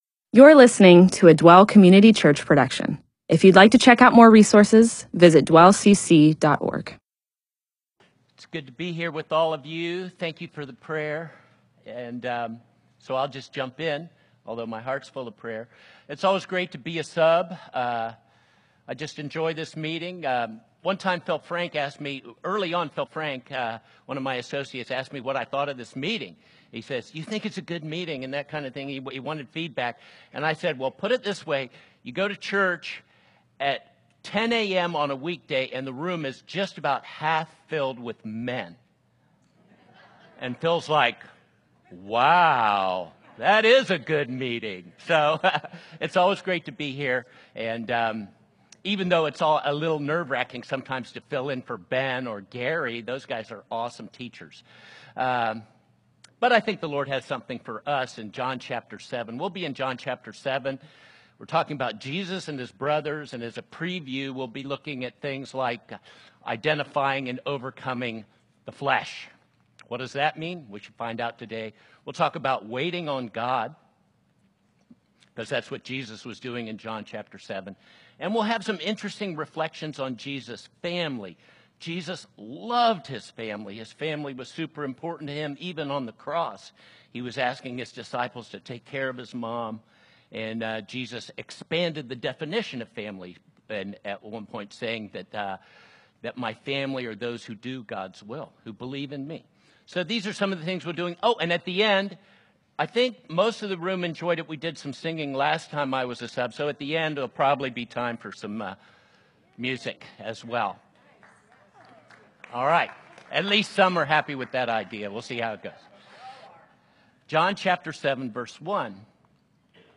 MP4/M4A audio recording of a Bible teaching/sermon/presentation about John 7:1-10.